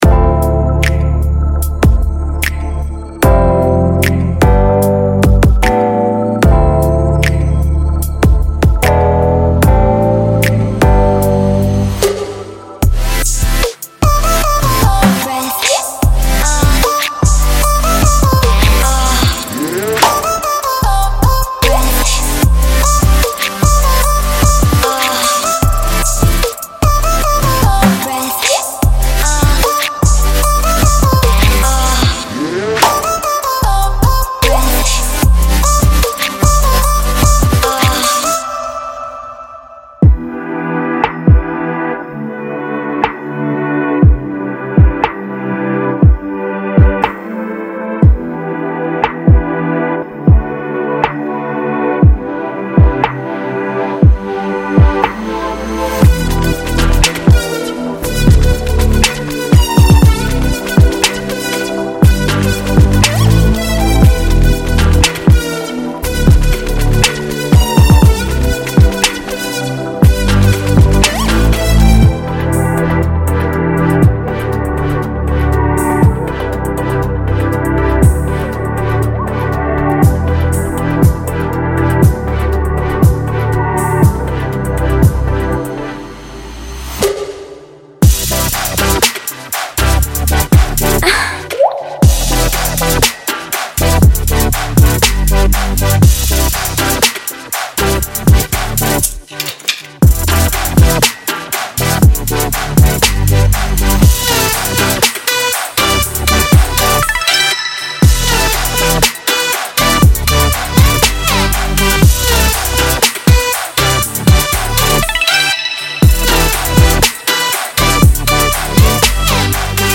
3. Future Bass
Serum和Massive是创建疯狂的Future Bass声音的两个最佳合成器。